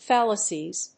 /ˈfælʌsiz(米国英語), ˈfælʌsi:z(英国英語)/